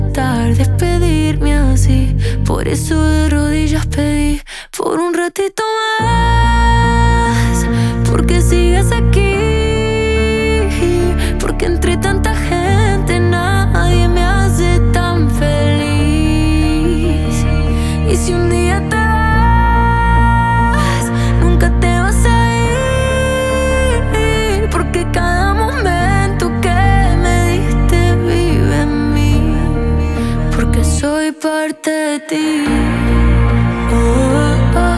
# Alternative and Latin Rock